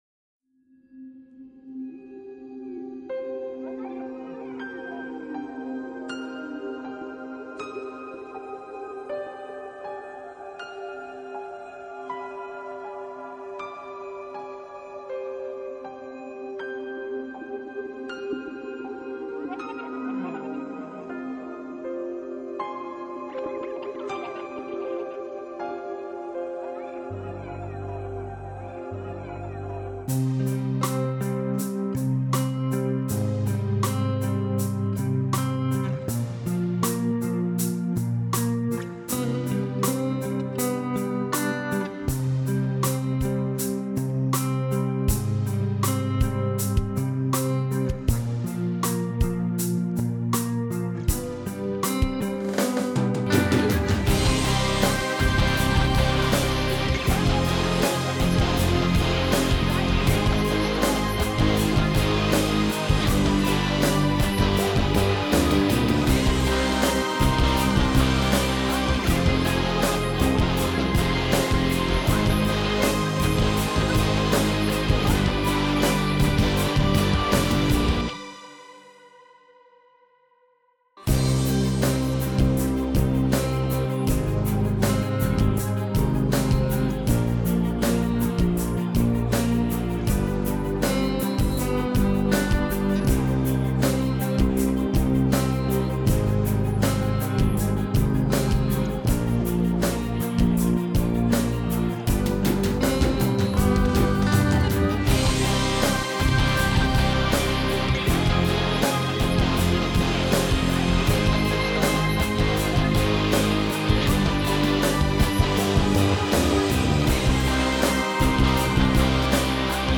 минусовка версия 35082